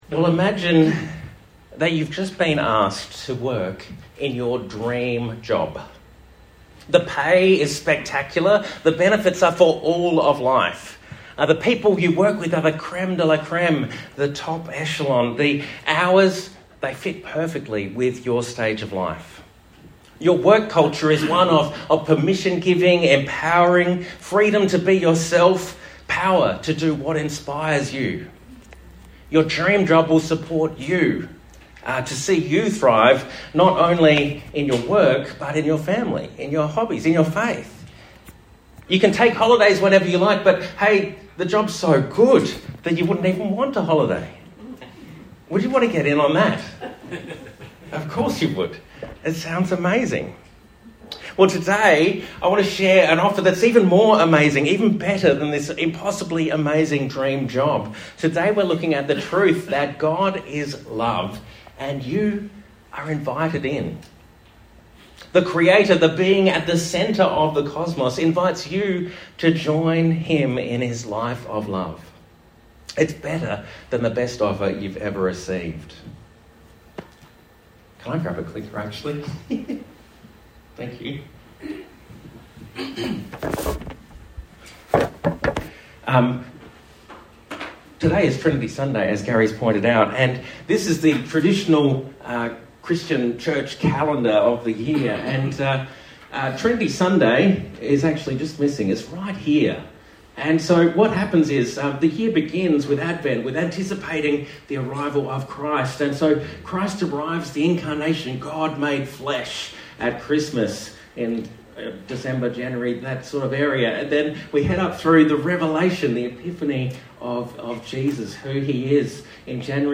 Sermon Resources - Anglican Church Noosa